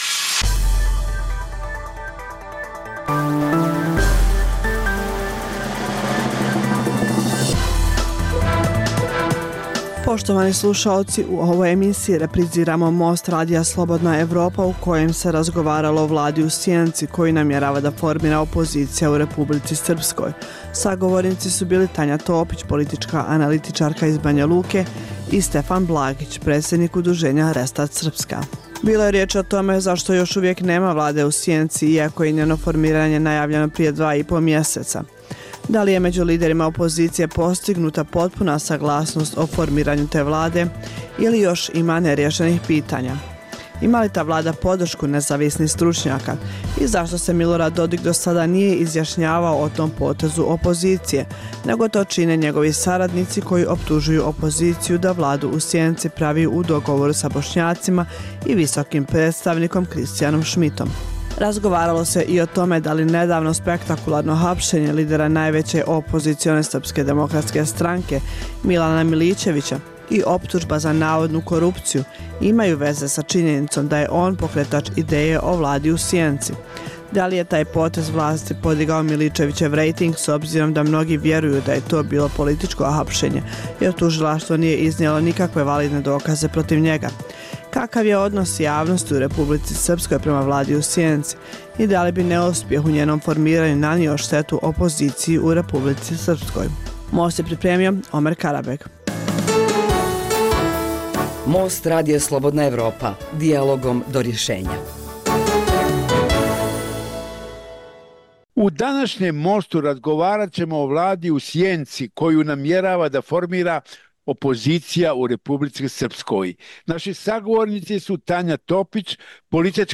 Dijaloška emisija o politici, ekonomiji i kulturi